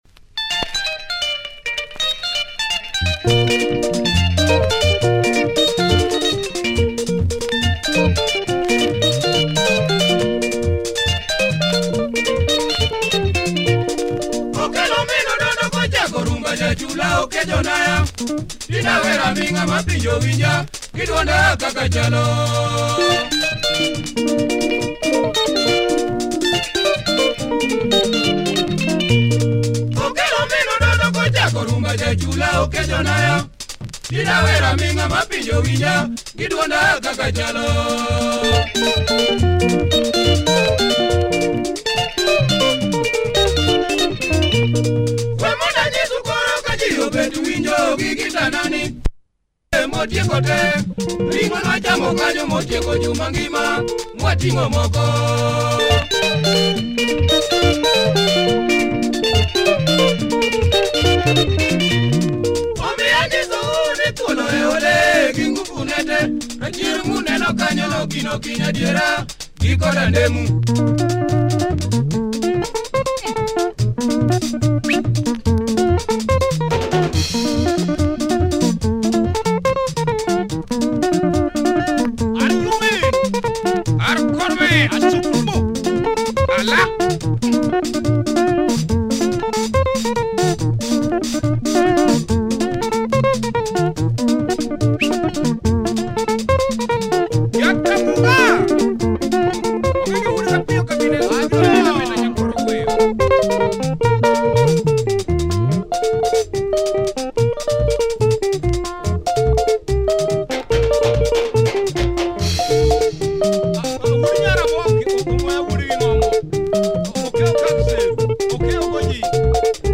luo benga